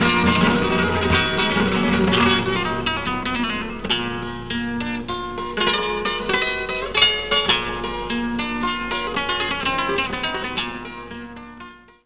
flamenco.wav